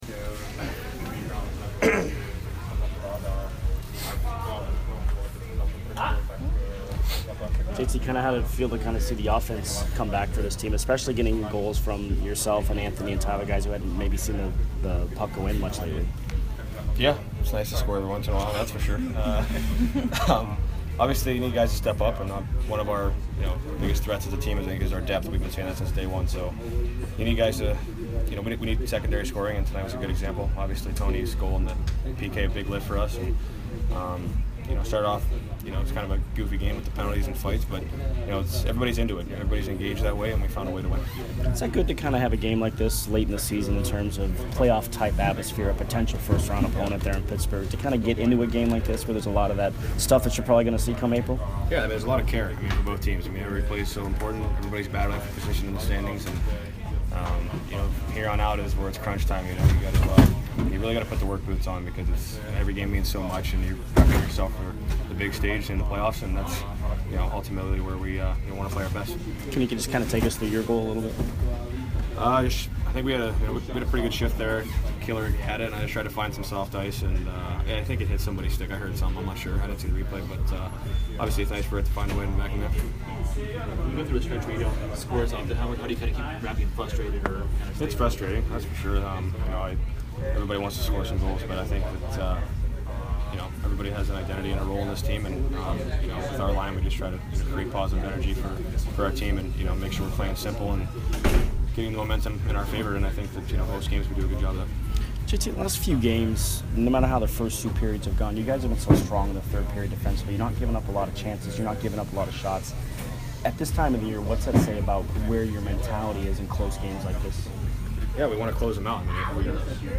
J.T. Miller post-game 2/9